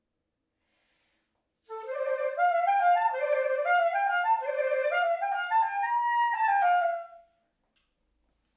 Played on historical clarinet